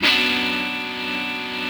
ChordBm.wav